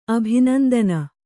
♪ abhinandana